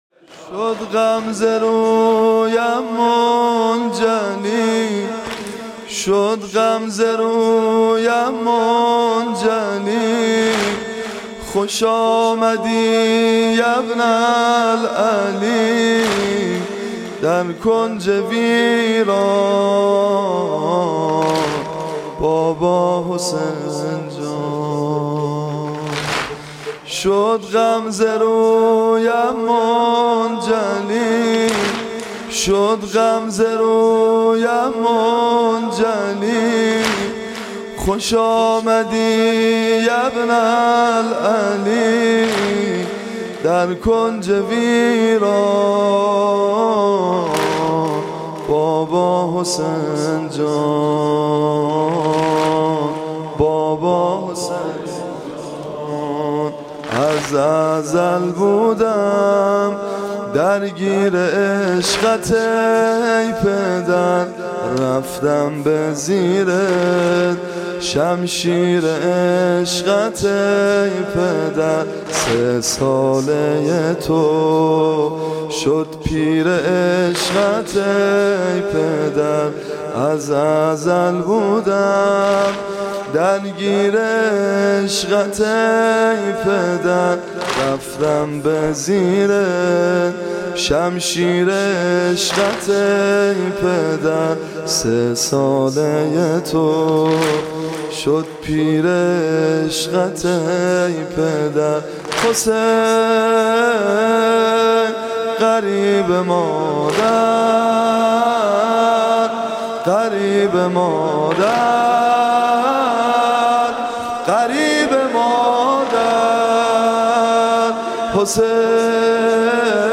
مداحی
صوت مراسم شب سوم محرم ۱۴۳۷ هیئت ابن الرضا(ع) ذیلاً می‌آید: